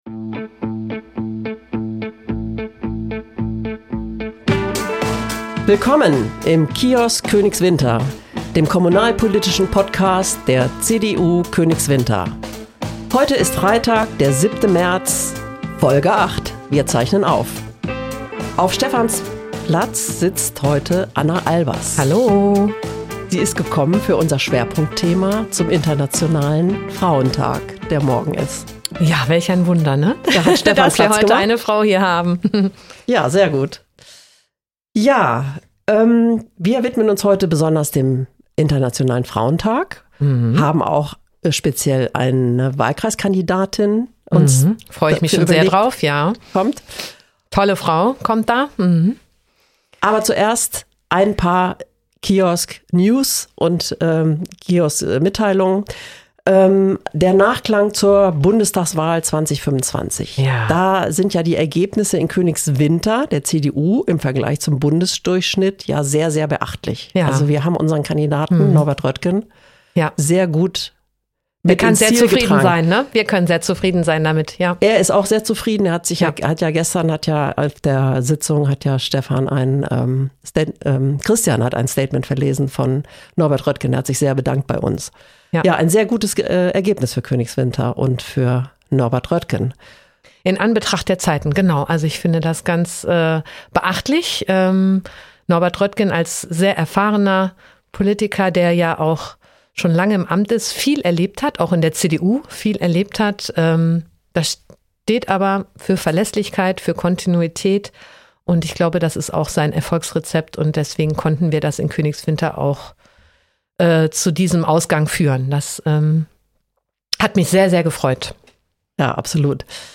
Beschreibung vor 1 Jahr Zum Internationalen Frauentag melden sich in dieser Folge nur Frauen zu Wort.